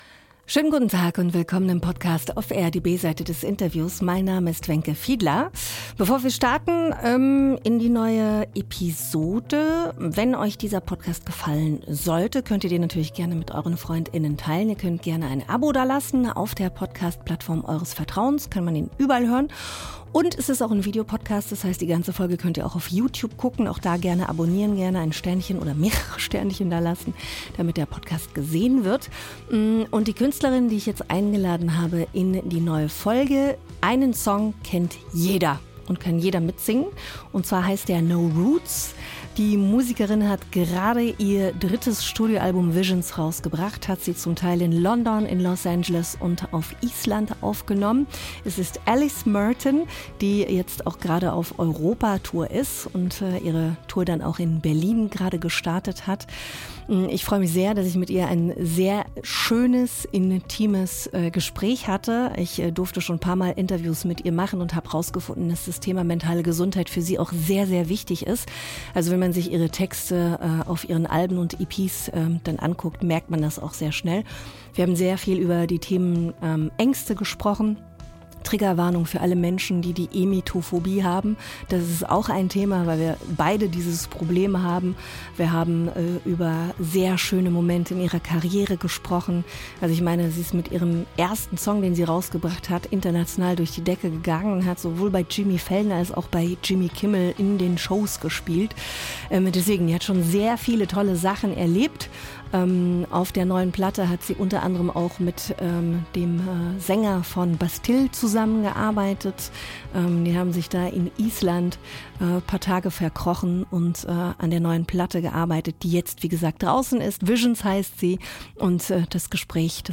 Ein ehrliches und tiefgehendes Gespräch über persönliche Herausforderungen, die Kraft der Musik und die positive Wirkung von Therapie.